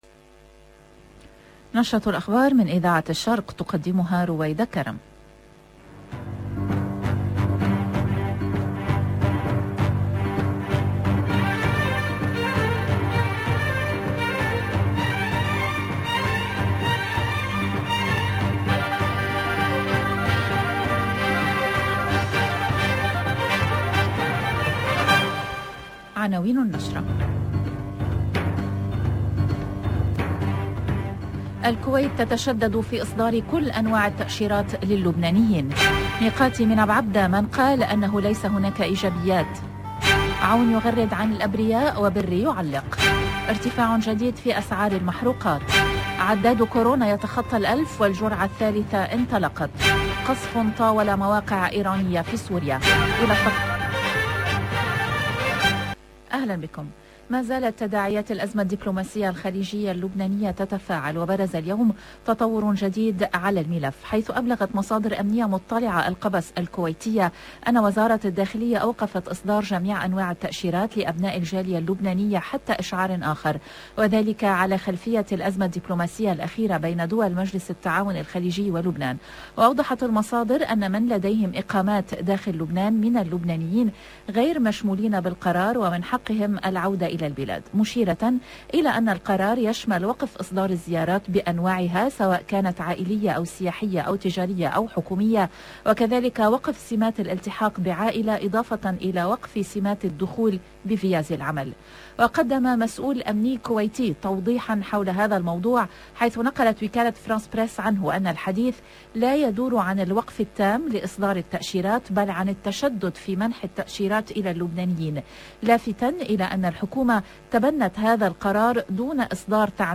LE JOURNAL EN LANGUE ARABE DU LIBAN DU SOIR DU 10/11/21